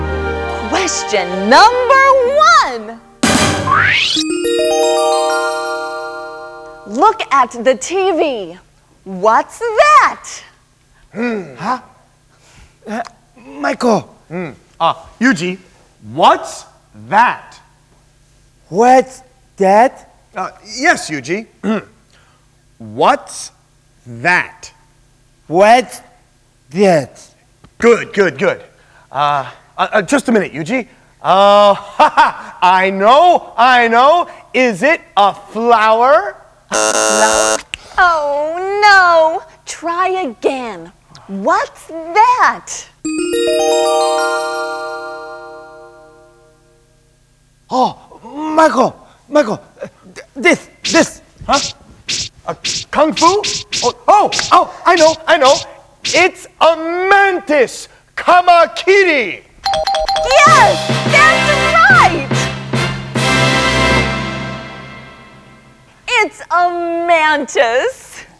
21 ON NET SKIT